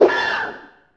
c_hunter_atk1.wav